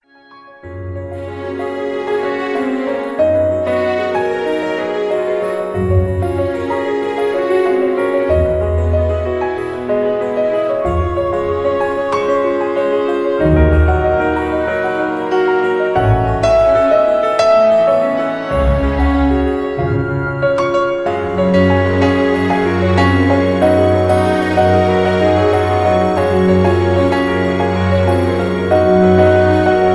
(Key-F#) Karaoke MP3 Backing Tracks